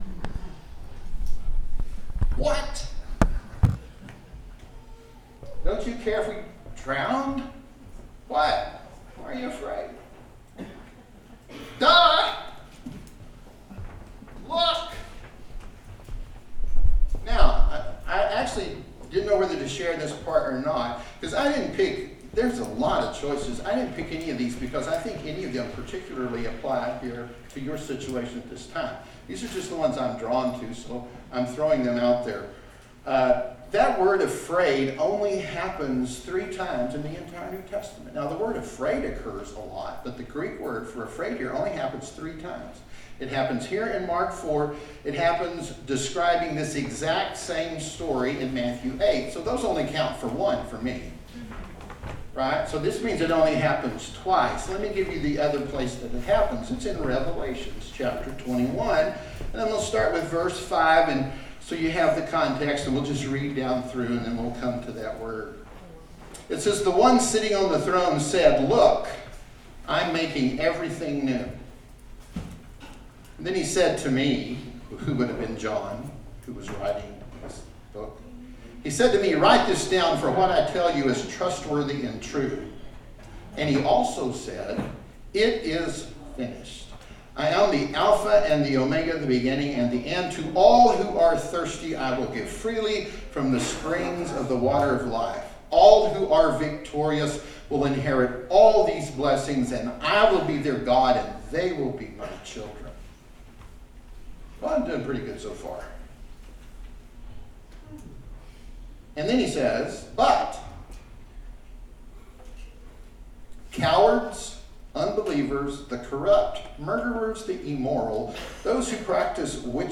Please note that a bit of the sermon at the beginning was missing due to technical difficulties.